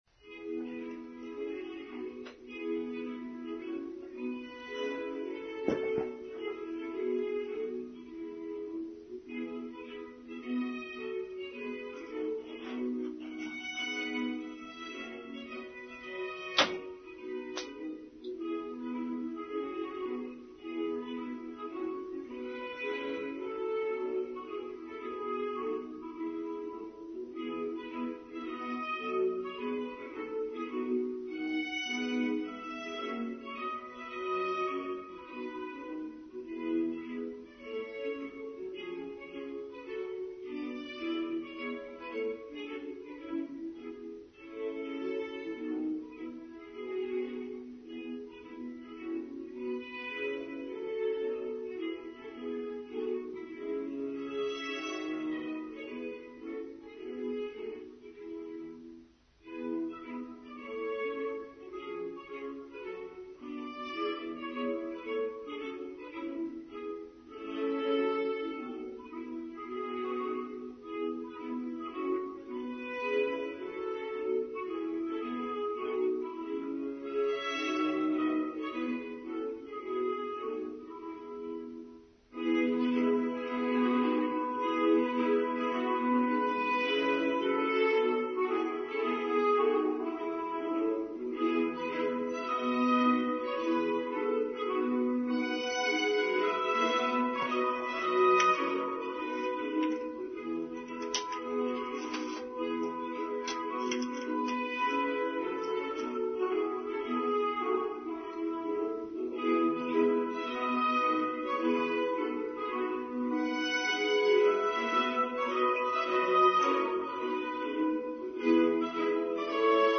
We Live in Time: Online Service for Sunday 4th February 2024